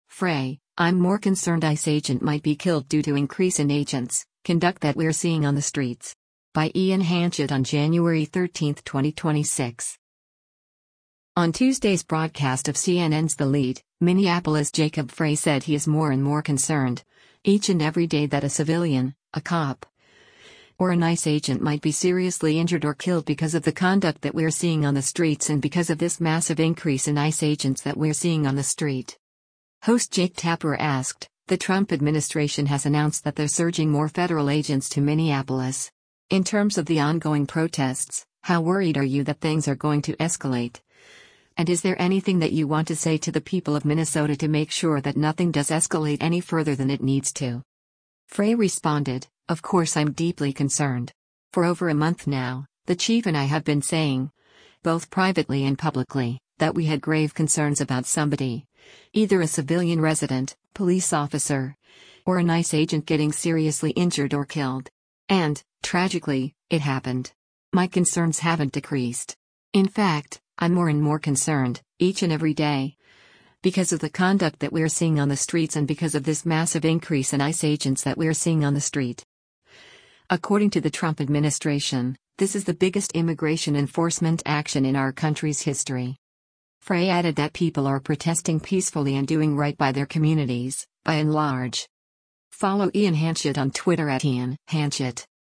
On Tuesday’s broadcast of CNN’s “The Lead,” Minneapolis Jacob Frey said he is “more and more concerned, each and every day” that a civilian, a cop, or an ICE agent might be “seriously injured or killed” “because of the conduct that we’re seeing on the streets and because of this massive increase in ICE agents that we’re seeing on the street.”